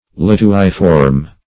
Search Result for " lituiform" : The Collaborative International Dictionary of English v.0.48: Lituiform \Lit"u*i*form\, a. [Lituus + -form.] Having the form of a lituus; like a lituite.